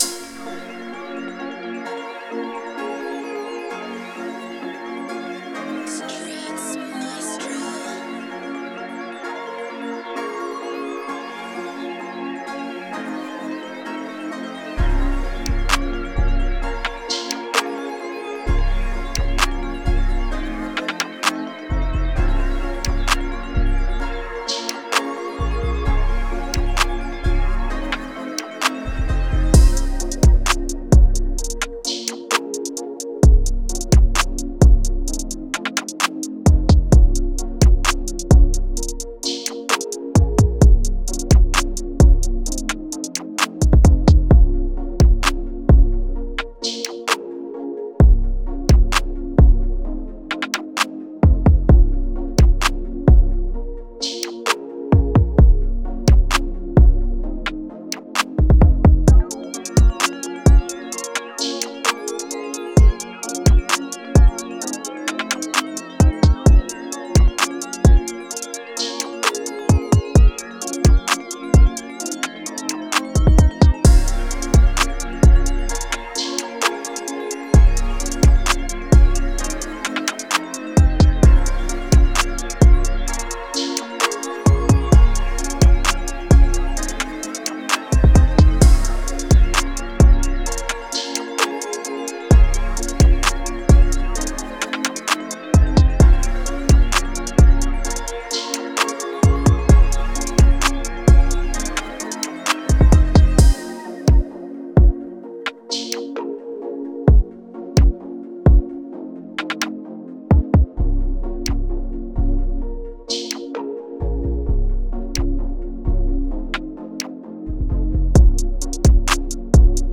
Moods: Intimate, laid back, smooth
Genre: R&B
Tempo: 130
BPM 147
intimate, laid back, smooth beat